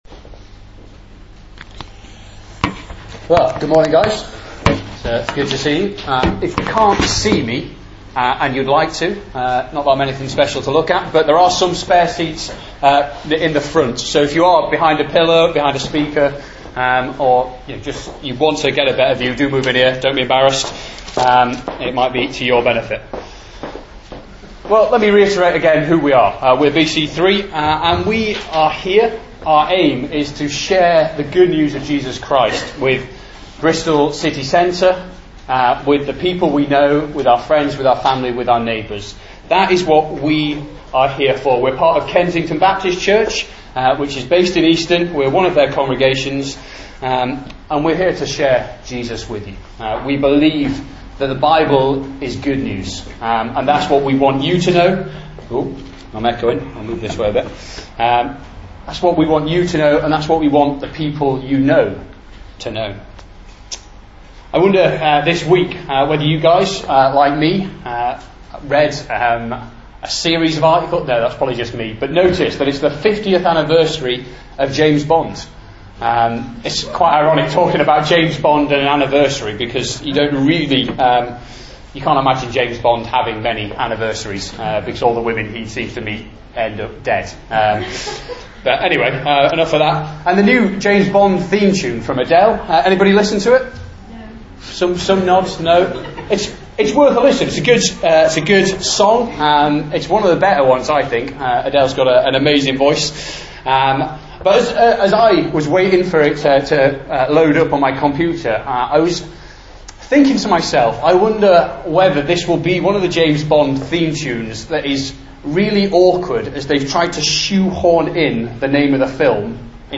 Sermons - Kensington